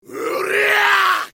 790bc3 sfiv zangief hooyah sound sound effects
790bc3-sfiv-zangief-hooyah-sound